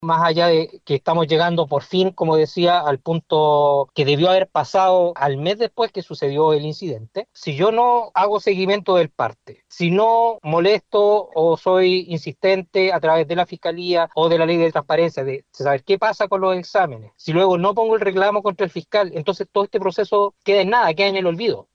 Así lo explicó en conversación con La Radio.